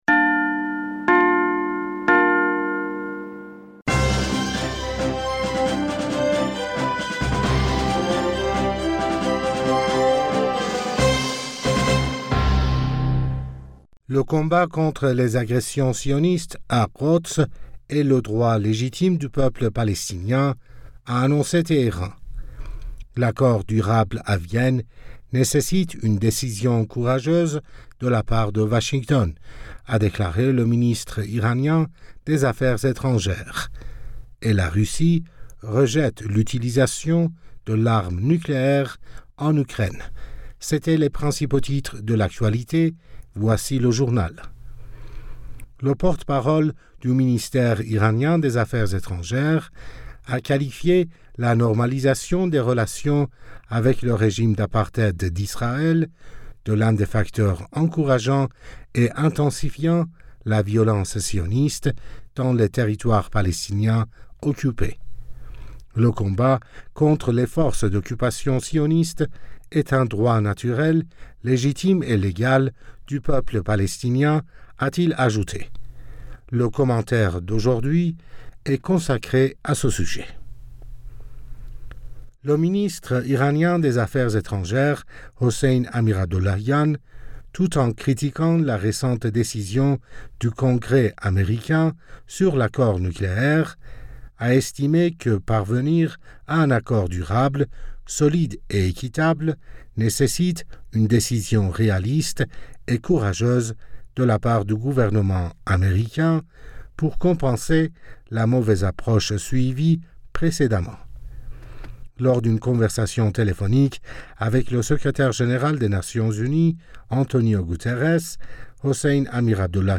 Bulletin d'information Du 07 Mai 2022